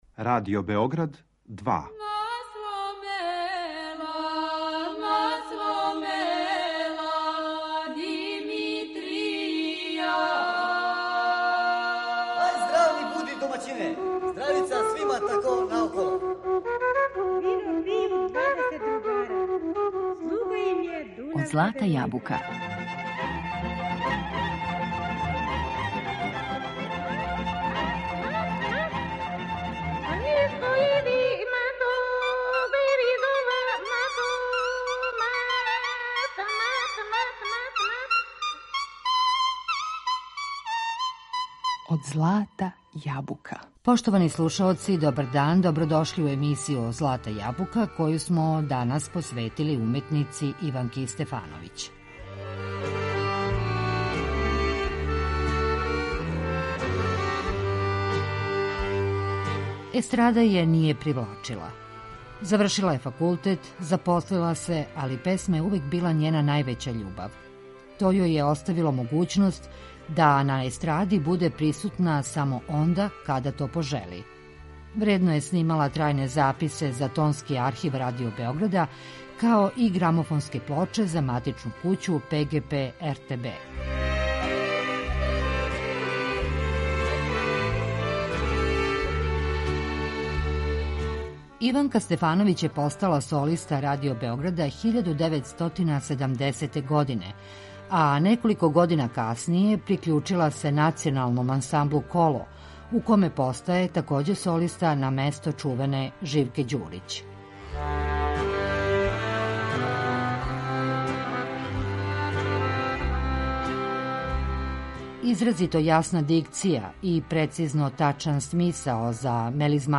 бисере нашег традиционалног народног стваралаштва